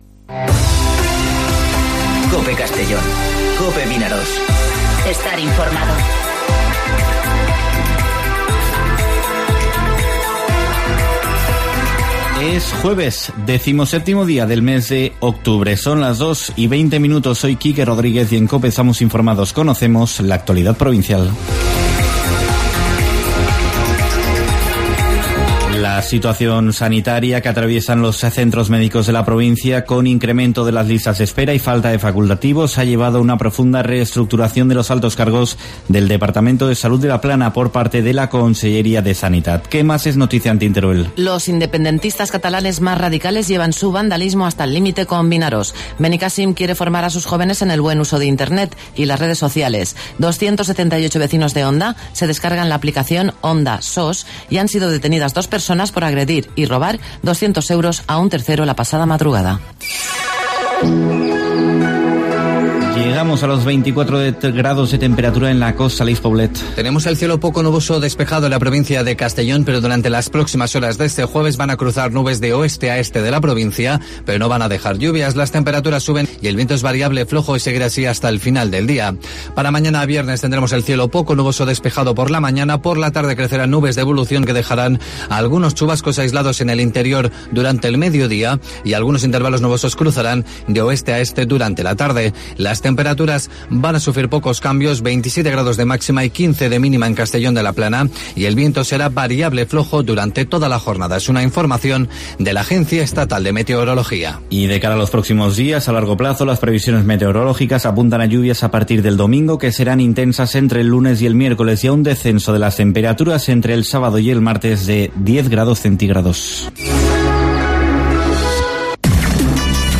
Informativo Mediodía COPE en Castellón (17/10/2019)